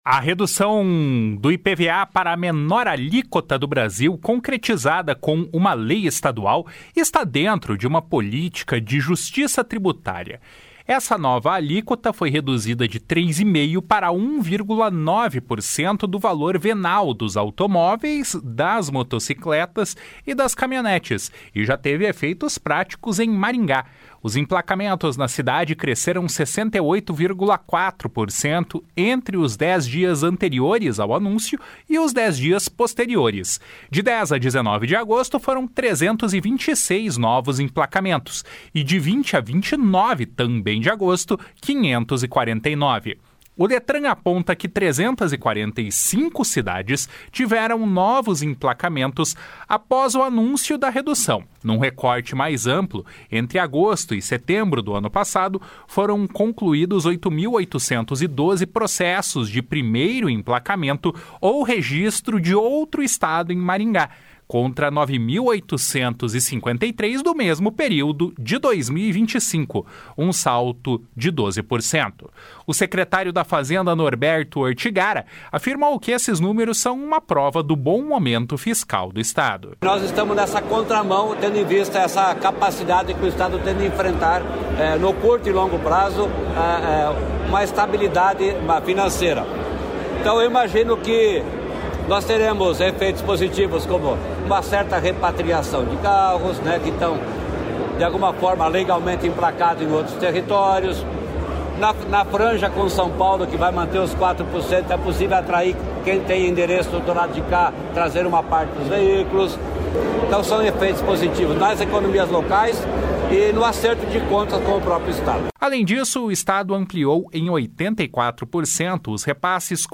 O secretário da Fazenda, Norberto Ortigara, afirmou que esses números são uma prova do bom momento fiscal do Estado. // SONORA NORBERTO ORTIGARA //